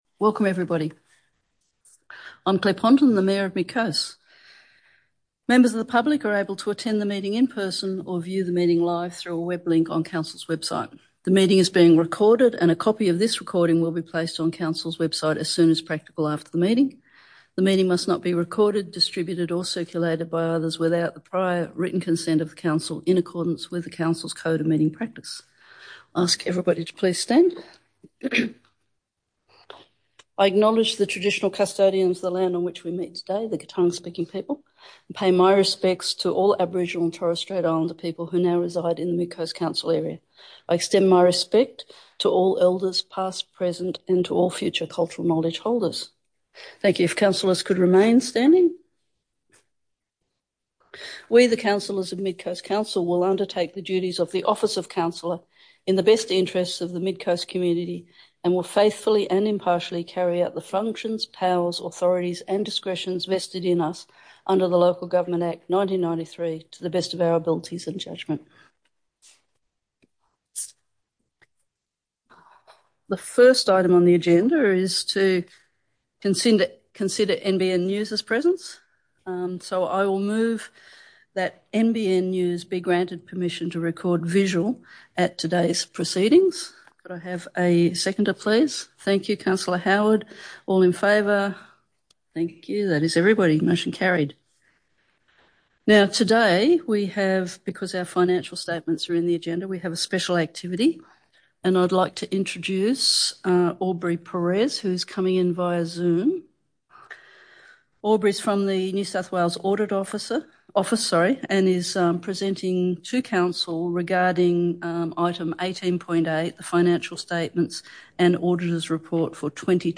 27 November 2024 Ordinary Meeting
Public Forum Audio Recording
Yalawanyi Ganya Council Chambers, 2 Biripi Way, TAREE SOUTH, 2430 View Map